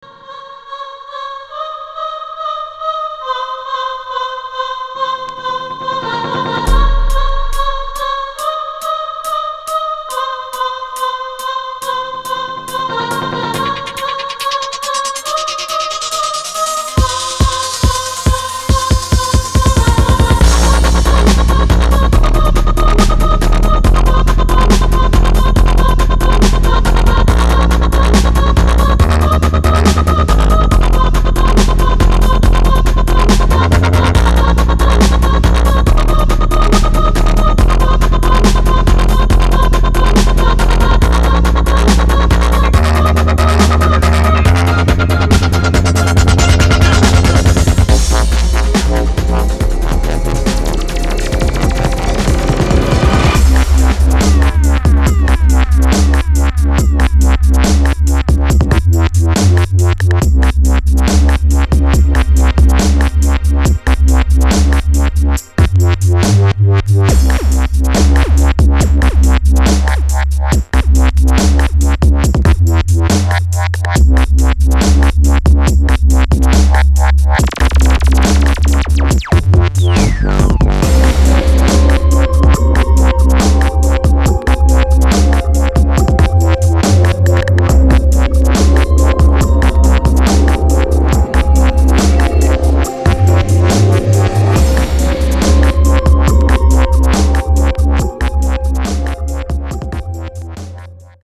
In: dubstep, retro, techno, trance
bass version